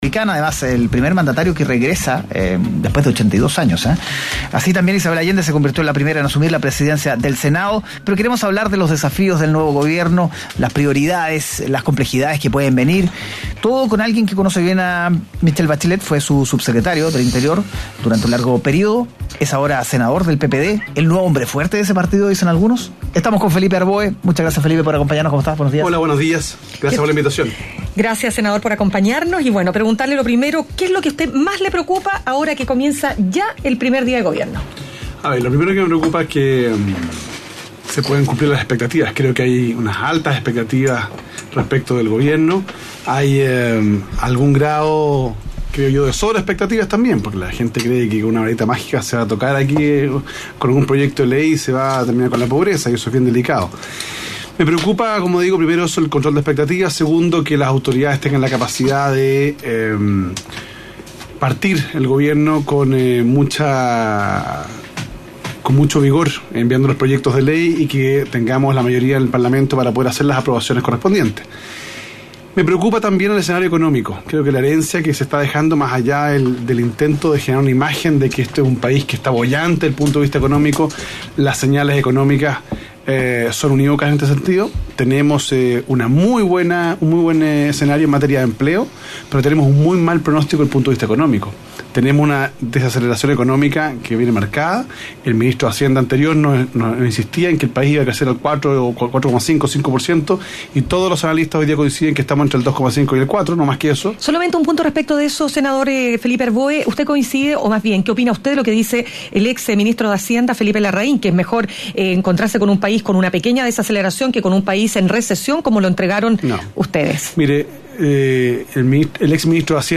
Este miércoles en Mañana Será Otro Día, conversamos con el abogado y senador por la VIII Cordillera, Felipe Harboe, respecto al actual gobierno de Michelle Bachelet.